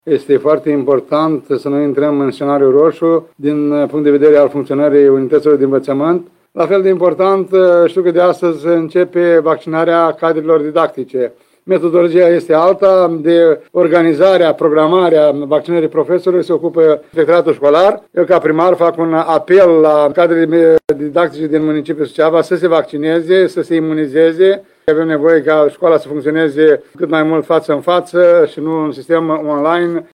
Primarul municipiului Suceava ION LUNGU a făcut apel la cadrele didactice să se vaccineze anti coronavirus, pentru a diminua incidența bolii.